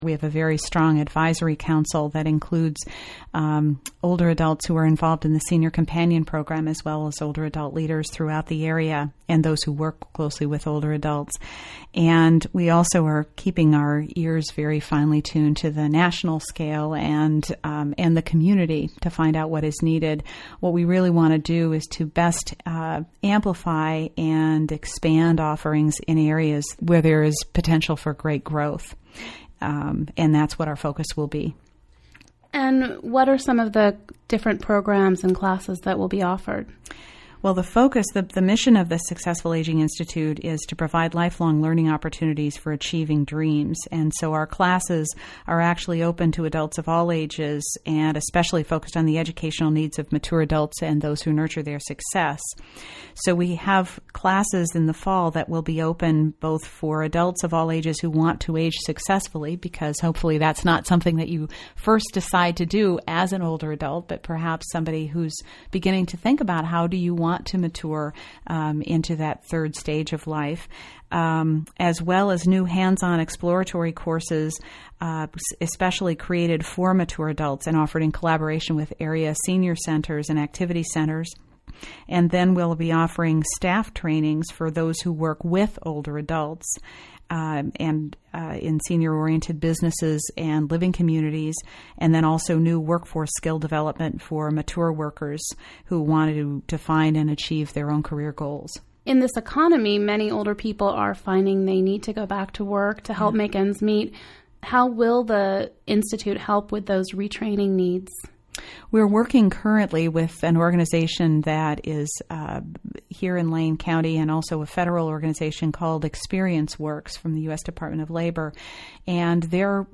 klcc-sai-interviewSept09.mp3